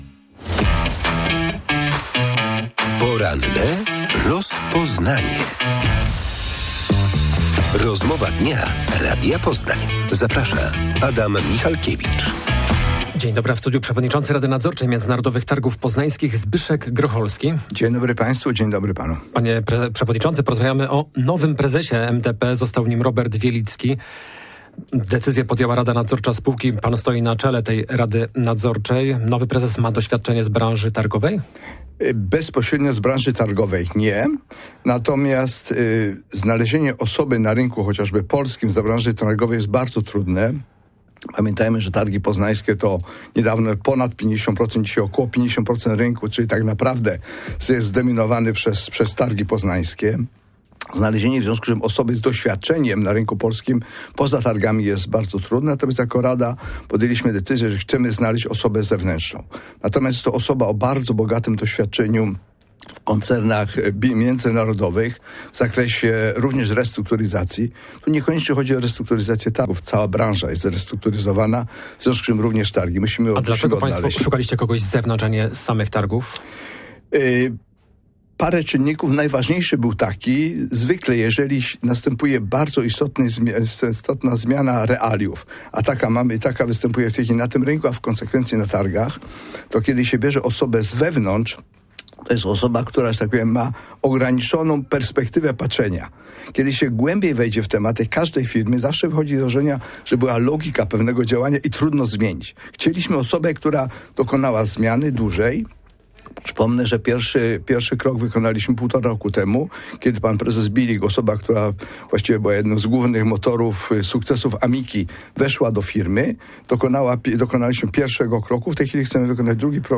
w porannej rozmowie Radia Poznań